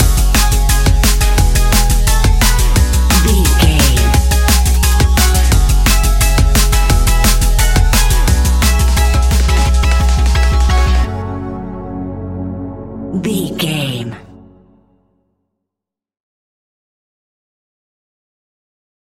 Ionian/Major
F♯
techno
trance
synths
synthwave
instrumentals